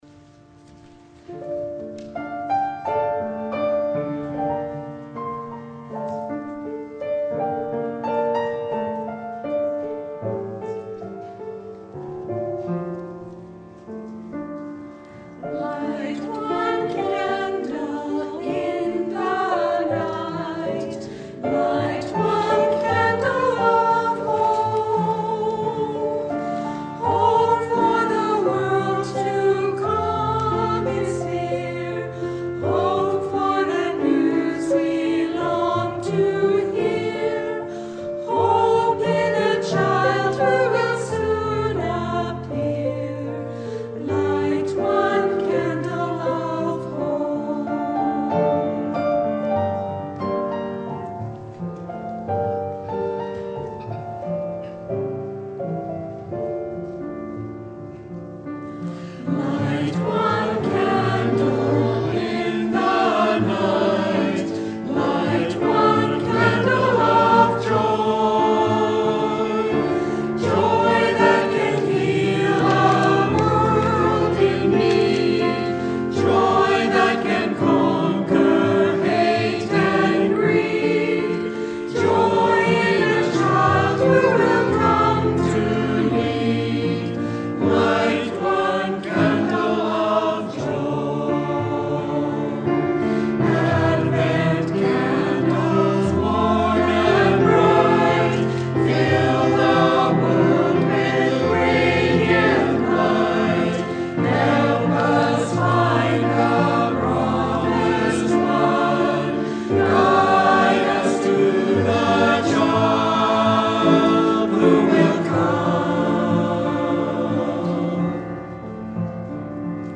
Christmas Eve Service December 24, 2018 7:00 p.m.